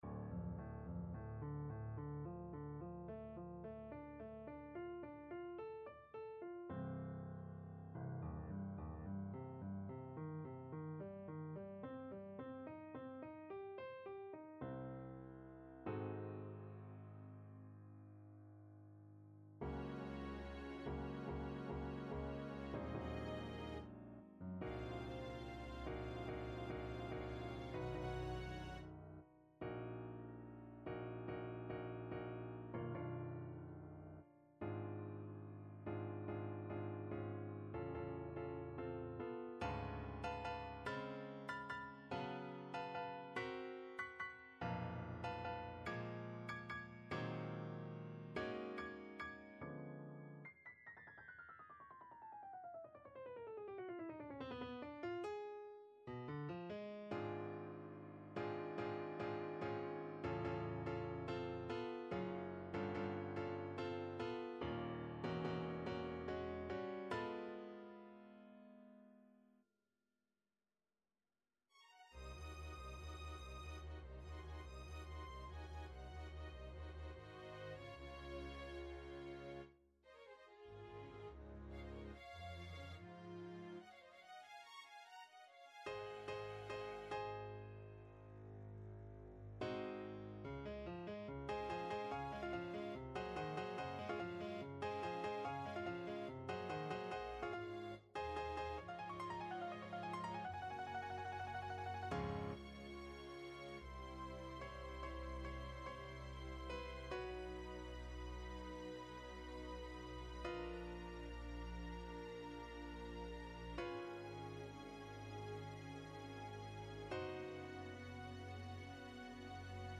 It's called a Mini Piano Concerto because it doesn't use a full orchestra but instead decently-sized sections of 5 string instruments First thing I have to say is that this is by no means the last complet...